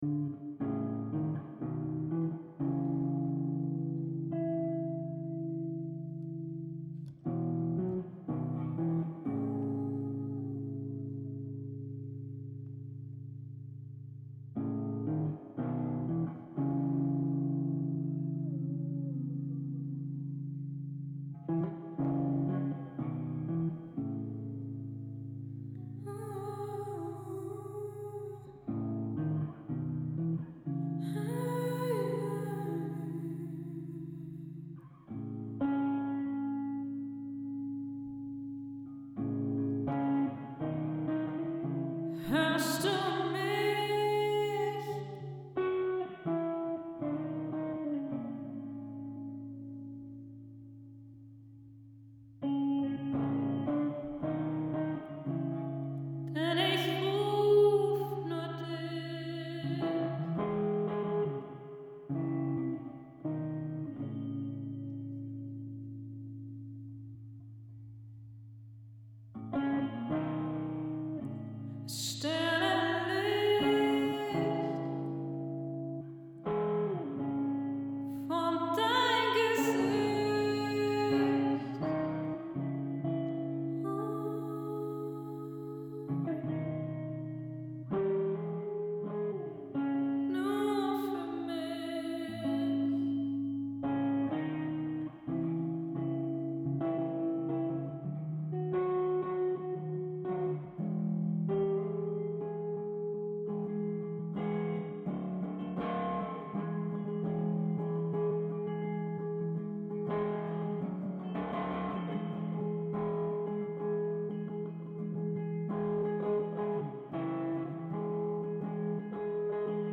jam session song